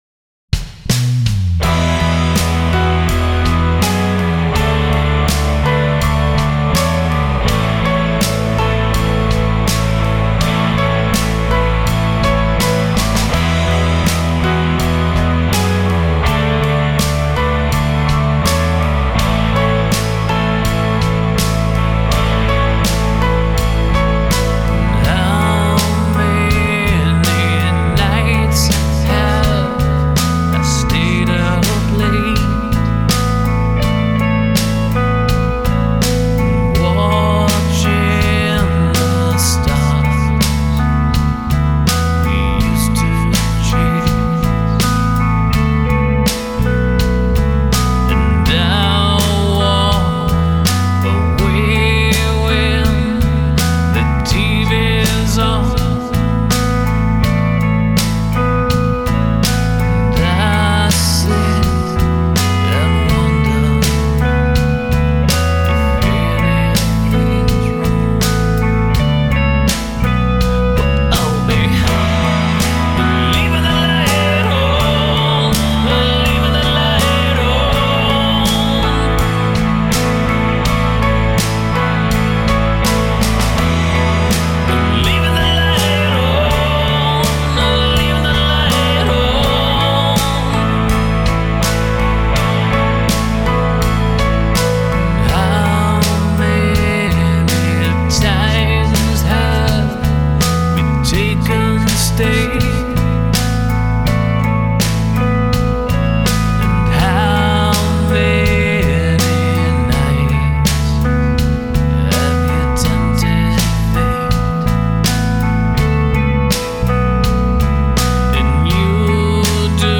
Recorded at Abbots Road Studio